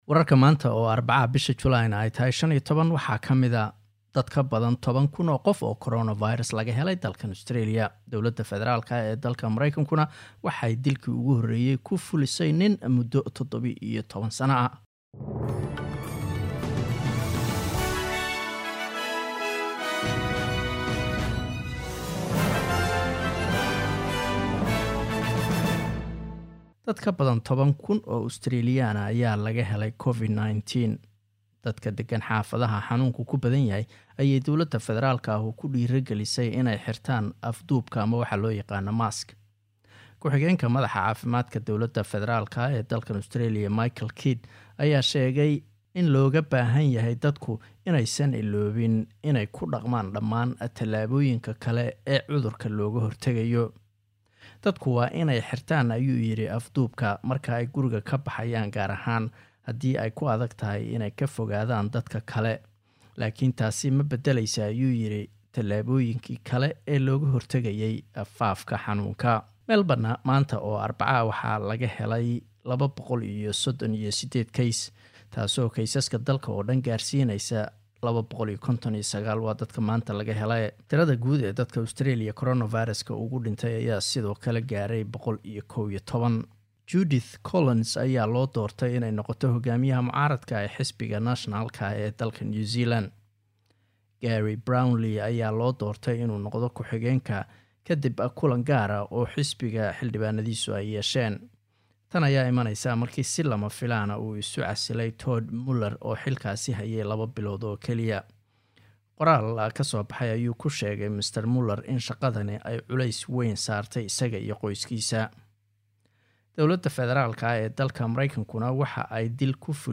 Wararka SBS Somali Arbaco 15 July